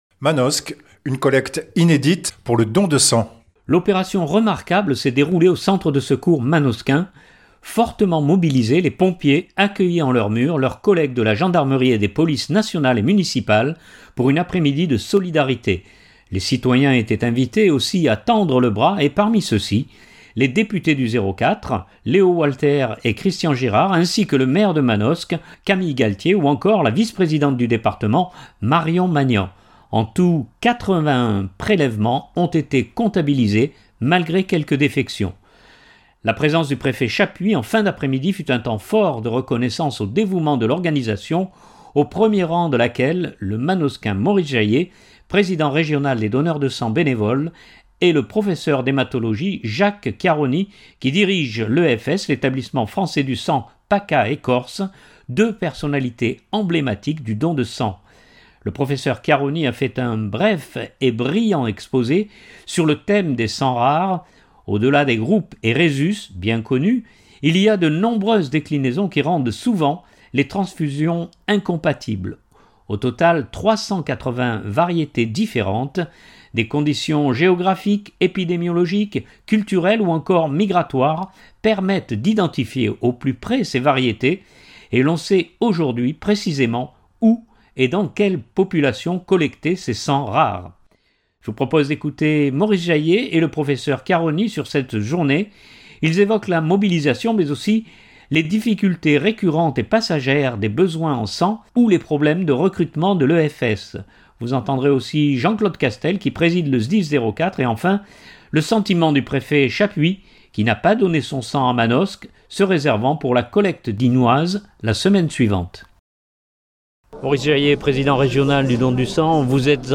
Ils évoquent la mobilisation mais aussi les difficultés récurrentes et passagères des besoins en sang ou les problèmes de recrutement de l’EFS. Vous entendrez aussi Jean-Claude Castel qui préside le SDIS 04 et enfin, le sentiment du préfet Chappuis qui n’a pas donné son sang à Manosque, se réservant pour la collecte dignoise la semaine suivante.